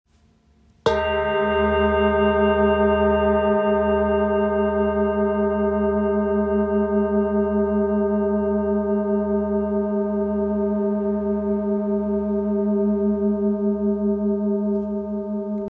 GSB Singing Bowl 23.5cm - 29.5cm
Light in weight yet remarkably strong in sound, this bowl produces deep, grounding vibrations that can be both felt and heard.